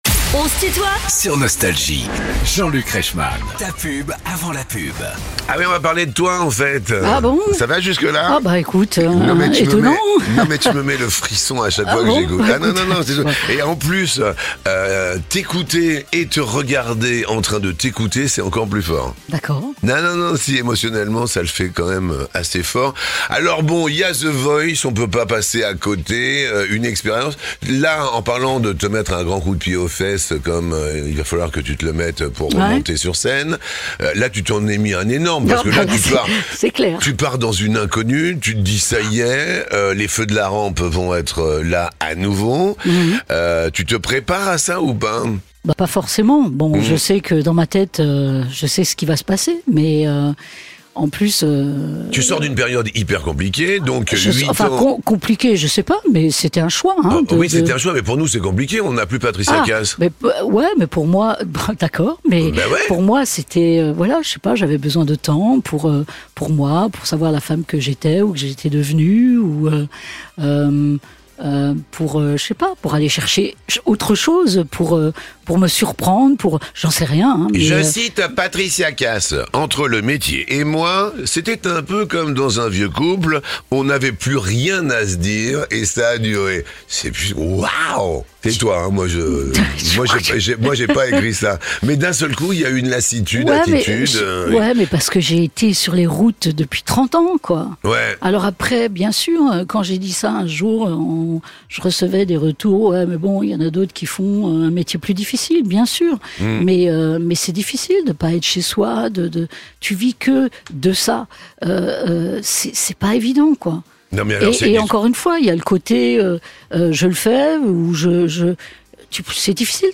Patricia Kaas invitée de "On se tutoie ?..." avec Jean-Luc Reichmann (Partie 2) ~ Les interviews Podcast